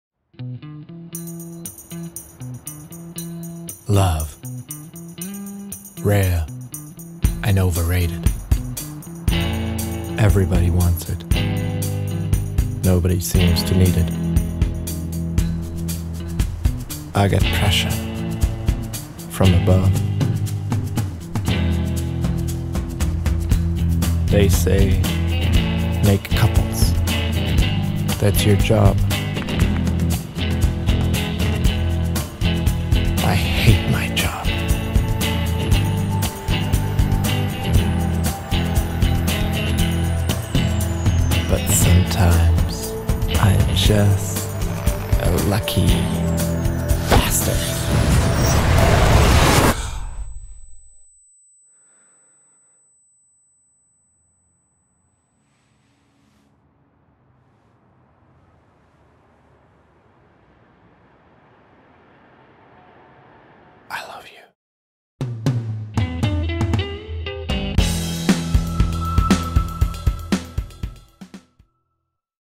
Mittel plus (35-65)
Wienerisch
Commercial (Werbung)